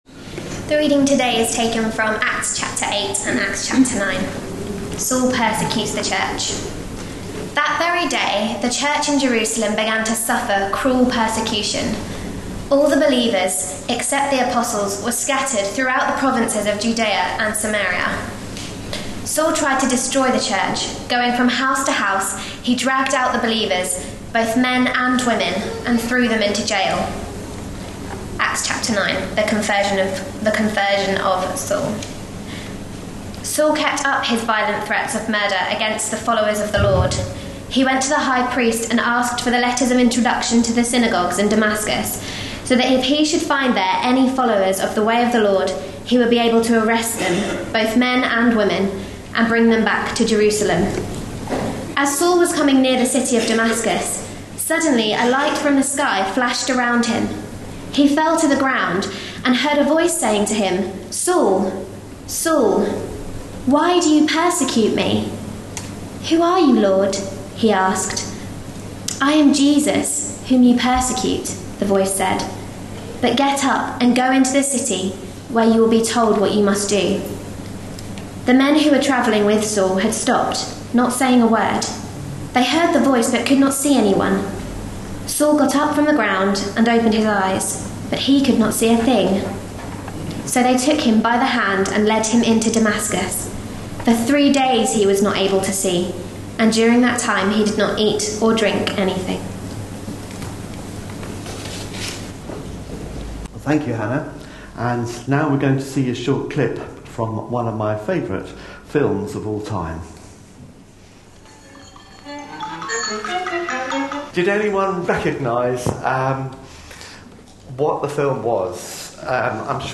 A sermon preached on 21st September, 2014.